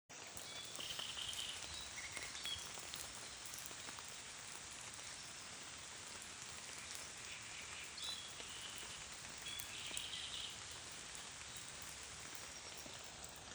Niedru strazds, Acrocephalus arundinaceus
Administratīvā teritorijaVecumnieku novads
StatussDzied ligzdošanai piemērotā biotopā (D)